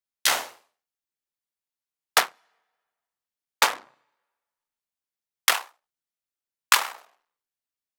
Drumkit sounds?
Is there any pack to be recommended when it comes to percussive sounds as close as a real drum kit?